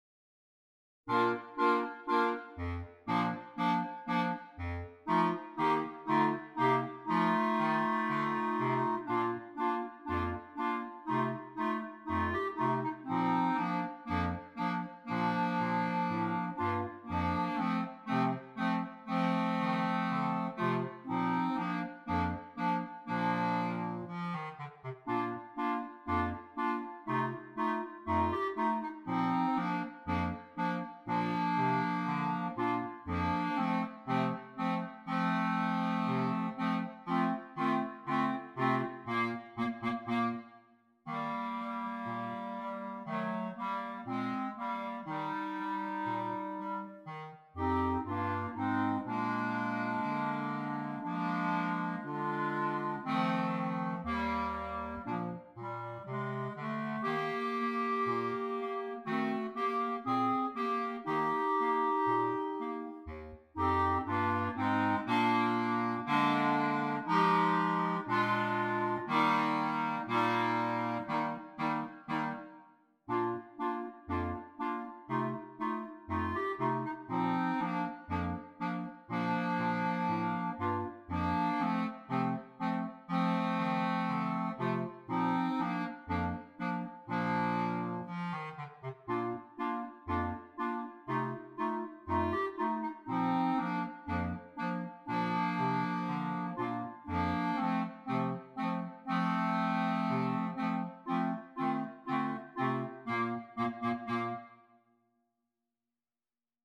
4 Clarinets, Bass Clarinet